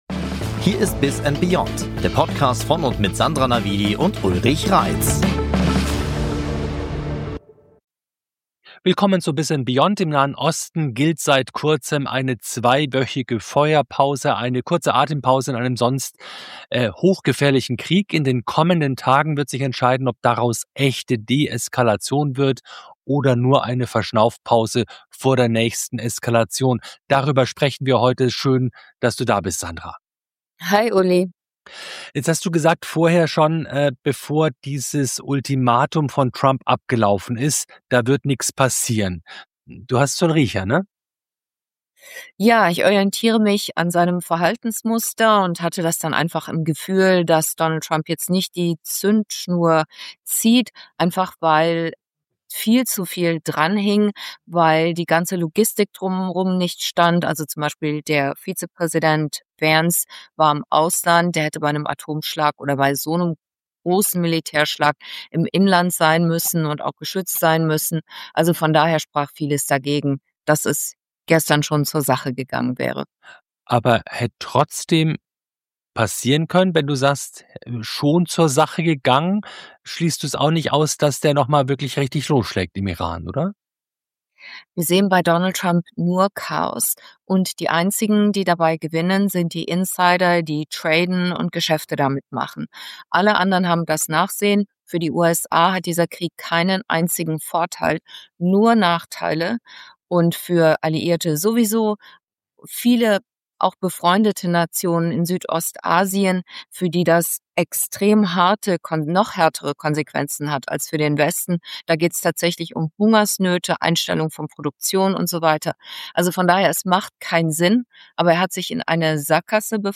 In dieser Folge von "Biz & Beyond" analysieren wir, wie US-Präsident Donald Trump den Iran-Konflikt in eine Sackgasse geführt hat – und warum es kein Zurück mehr zum „alten Amerika“ gibt. Ein Gespräch über Macht, Märkte und ein System, das aus dem Gleichgewicht geraten ist.